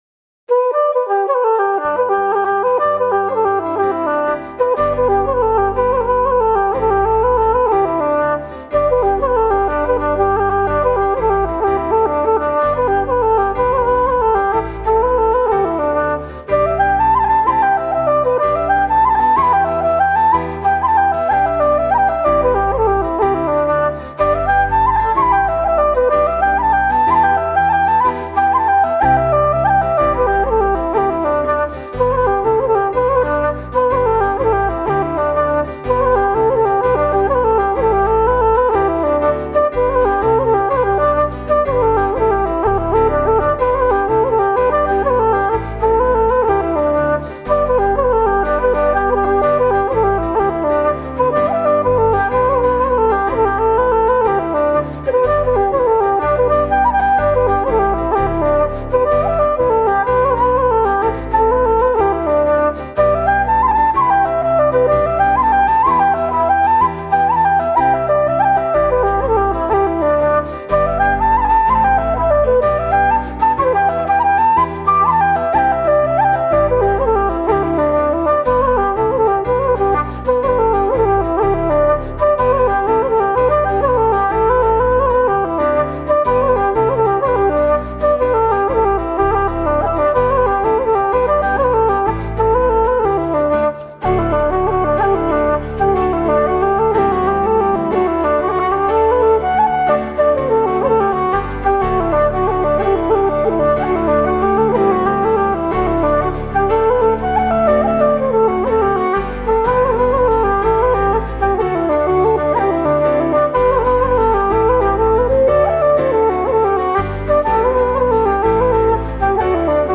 Traditional Irish Music - learning resources
Traditional Irish Music -- Learning Resources Battering Ram, The (Jig) /